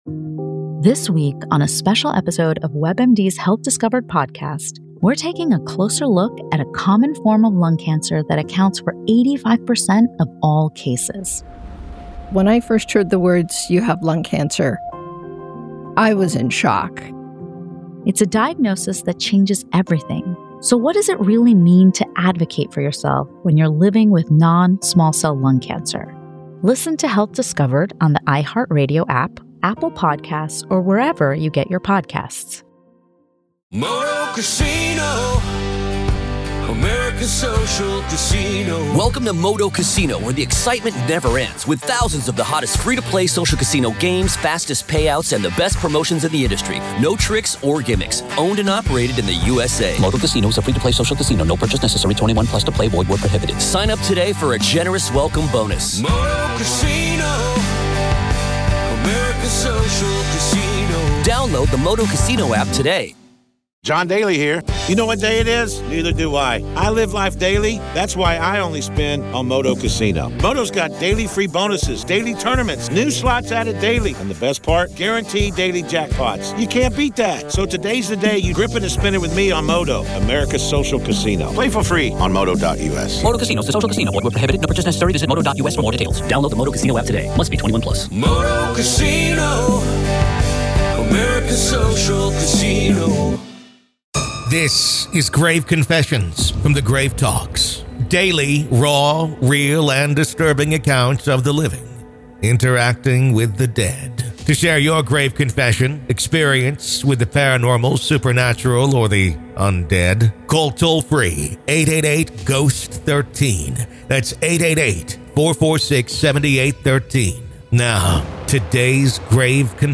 This is a daily EXTRA from The Grave Talks. Grave Confessions is an extra daily dose of true paranormal ghost stories told by the people who survived them!